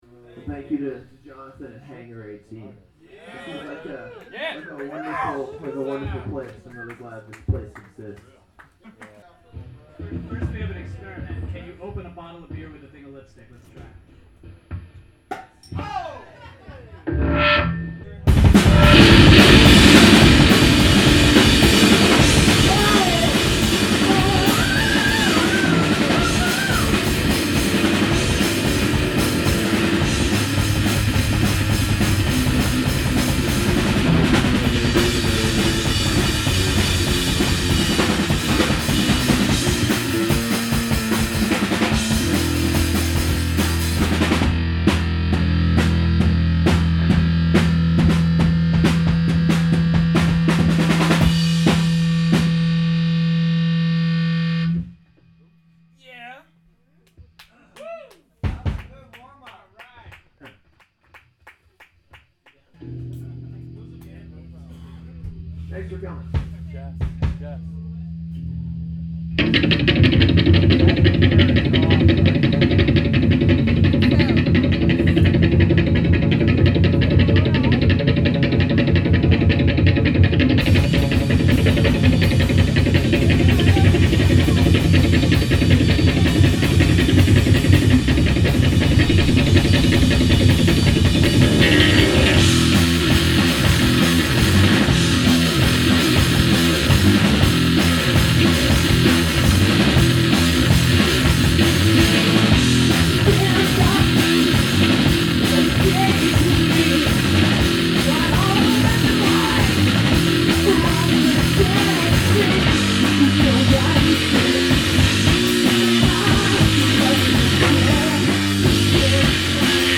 vibraphone